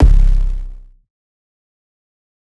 archived music/fl studio/drumkits/goodtakimu drumkit/808s